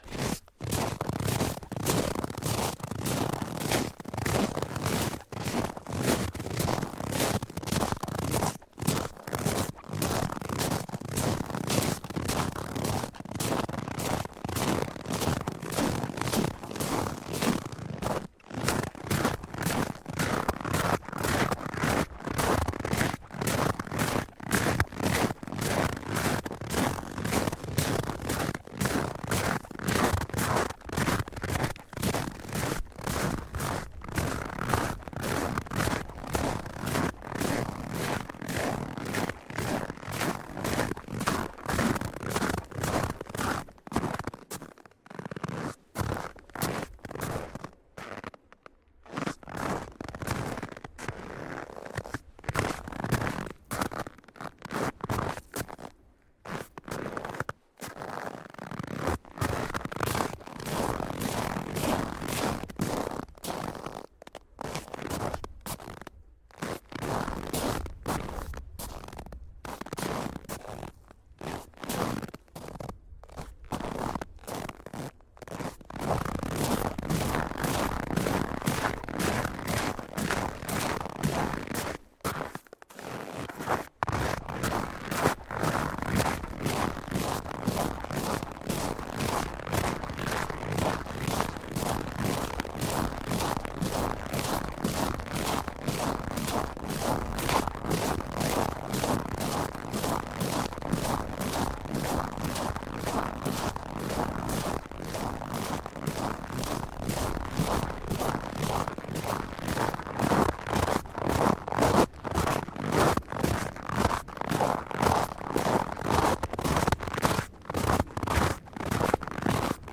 脚步声 " 脚步声 雪地里走来走去
描述：在冬季的一天，关闭一个人在雪地里的脚步声。雪是干的，所以声音很脆。人走向和远离麦克风。使用Neumann KMR 81i录制，声音设备744 T.
Tag: 逼近 脚步声 关闭 脆脆的 冬天